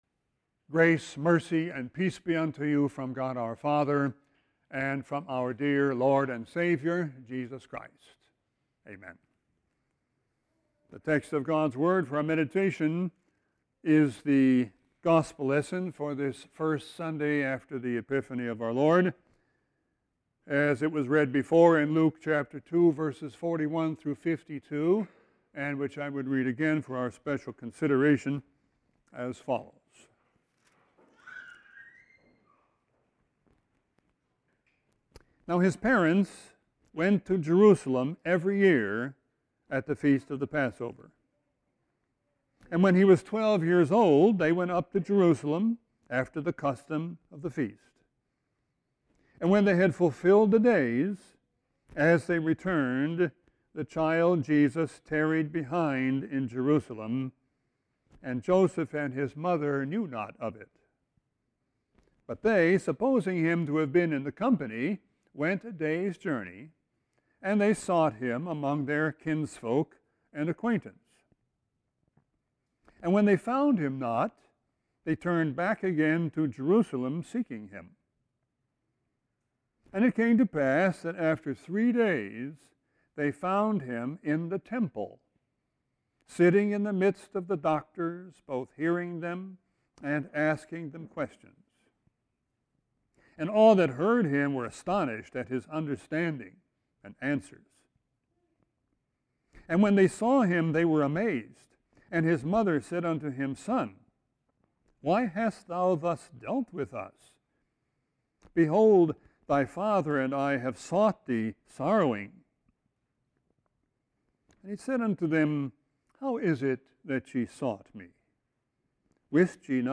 Sermon 1-13-19.mp3